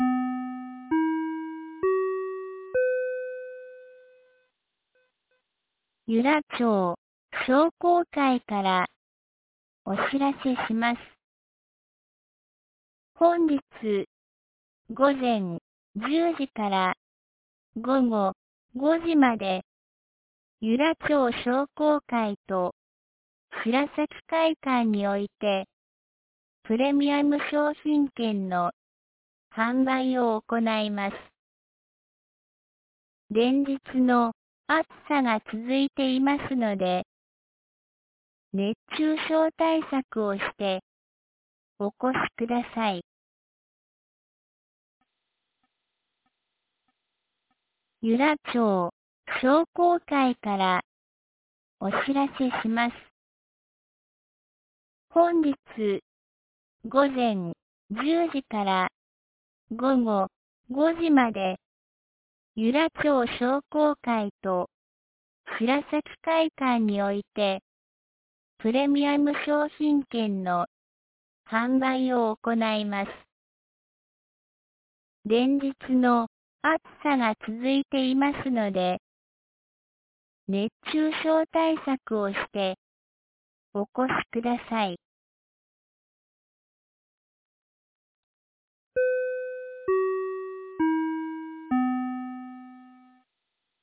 2025年09月02日 07時52分に、由良町から全地区へ放送がありました。